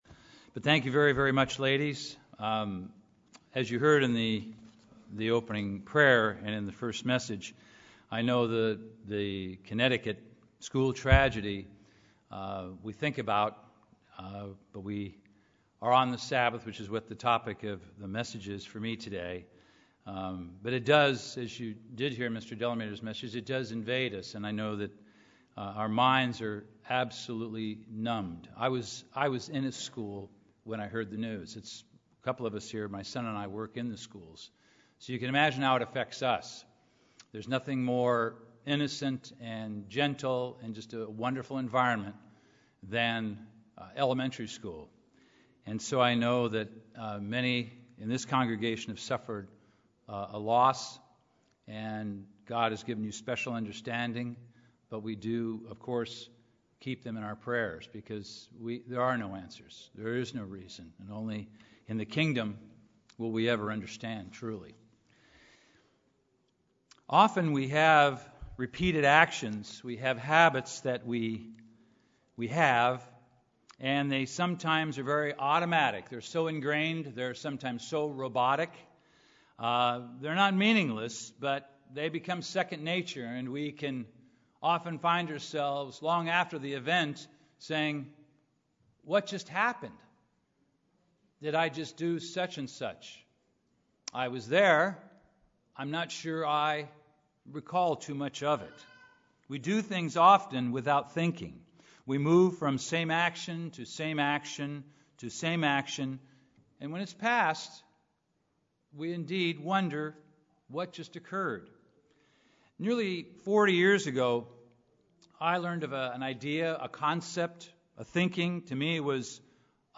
Given in Los Angeles, CA
Print This message discusses the significance of God's Holy Sabbath Day, and expounds upon three important aspects of the Sabbath: - Rest - Redemption - Service UCG Sermon Studying the bible?